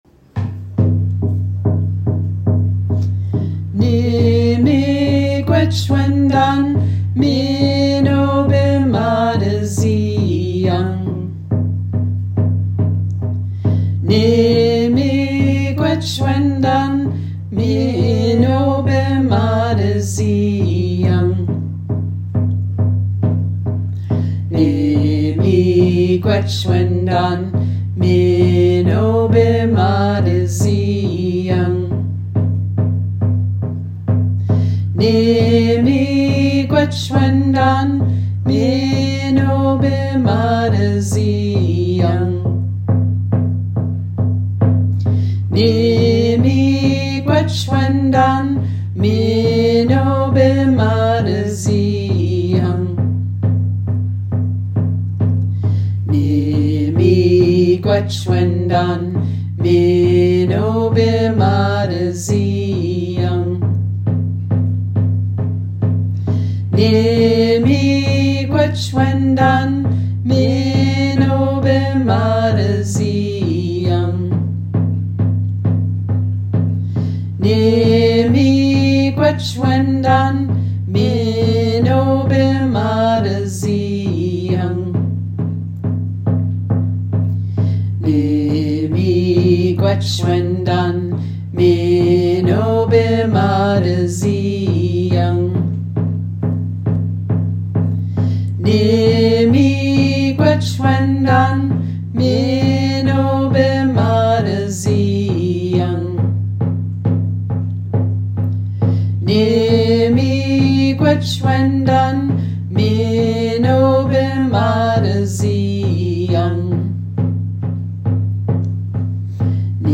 This version of the song was recorded by the women’s hand drum group, Miskwaasining Nagamojig (Swamp Singers) in Ann Arbor, MI, 2012.